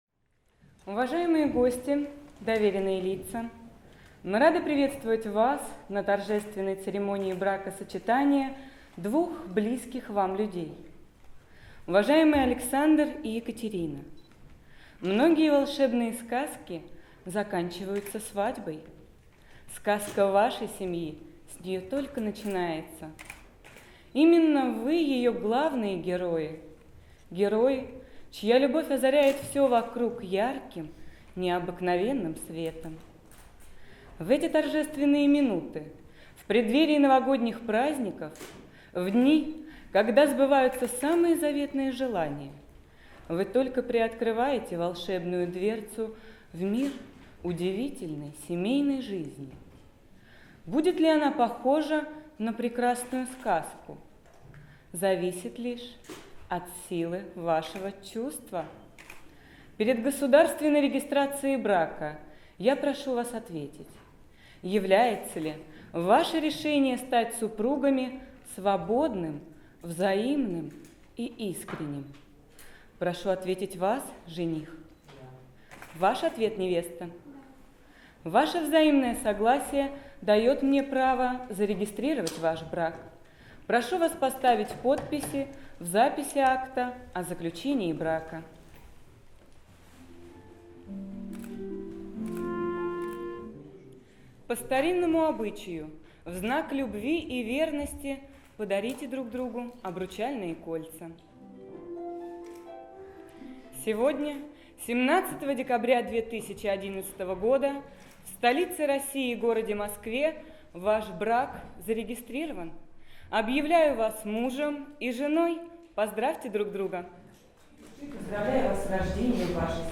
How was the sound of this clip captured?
Wedding ceremony.